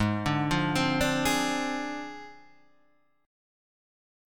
G#6b5 chord